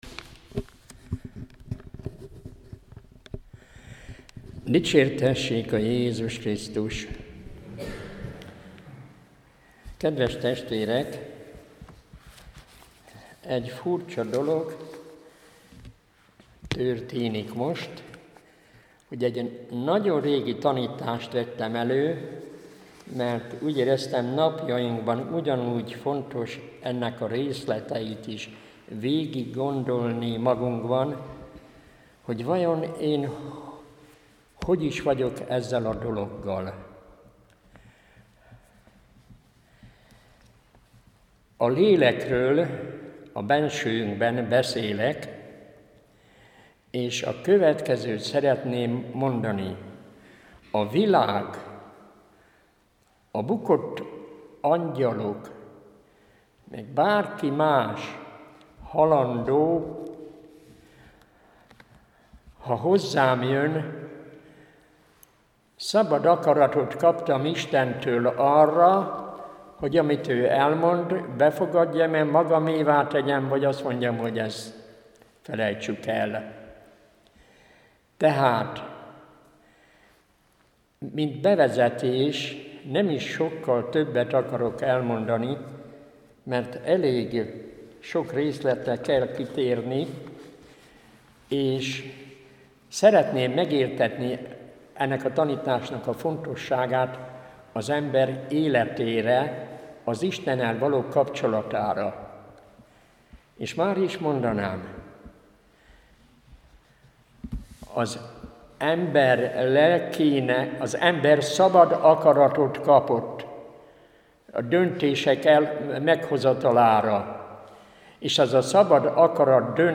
A tanítás Siennai Szt. Katalin Dialógus című művének 144. fejezetén alapszik.